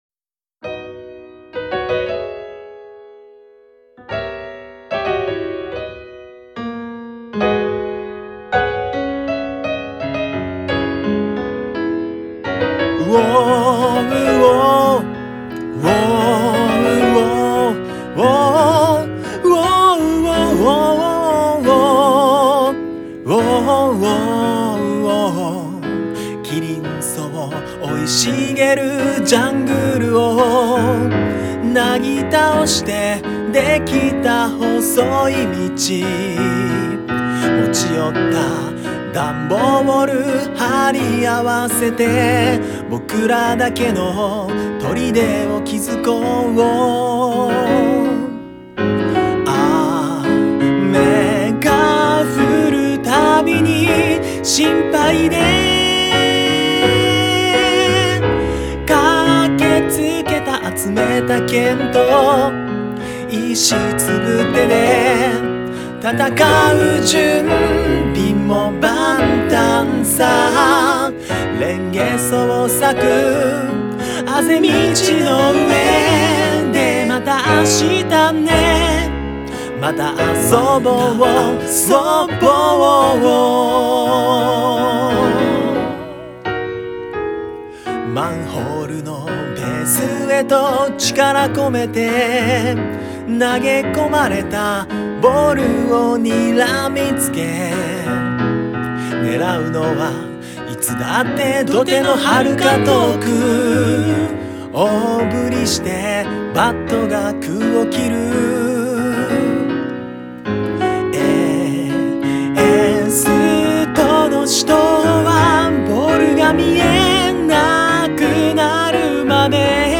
Arrange/Piano